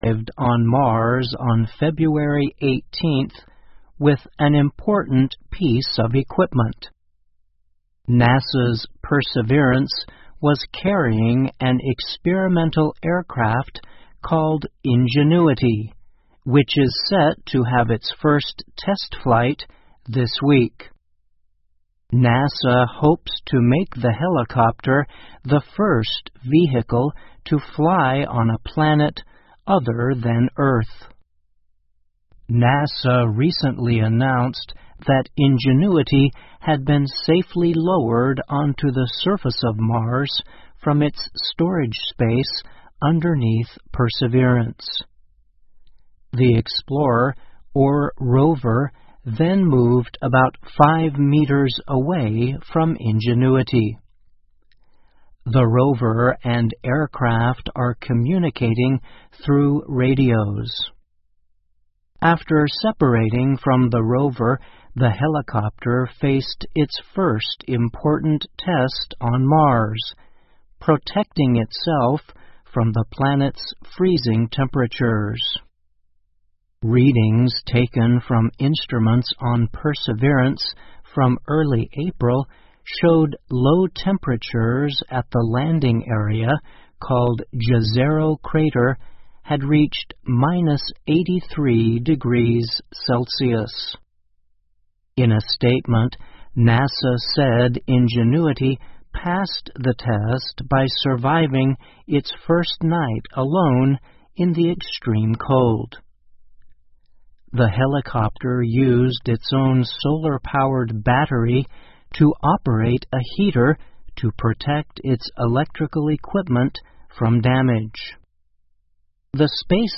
VOA慢速英语--美国宇航局准备在火星上进行首次直升机飞行试验 听力文件下载—在线英语听力室